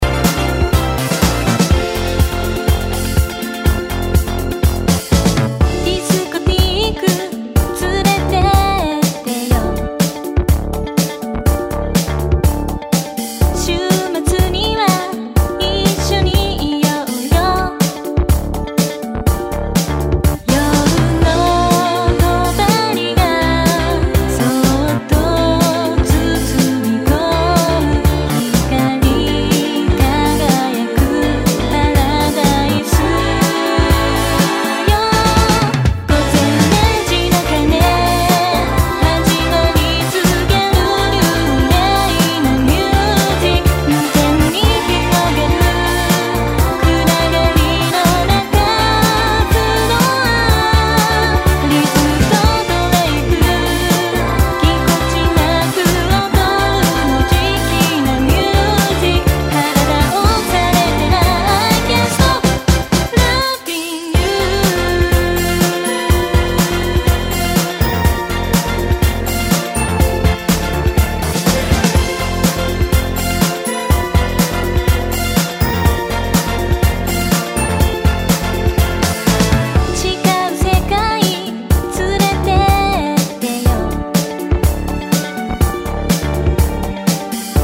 POP# 90-20’S アイドル